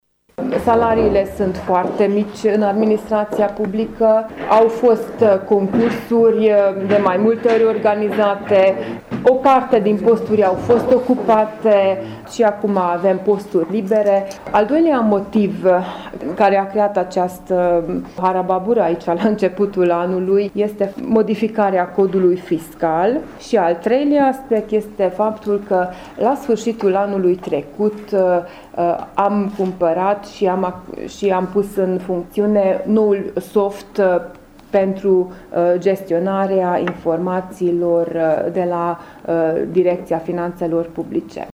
Sztakics Eva, viceprimarul municipiului Sfântu Gheorghe: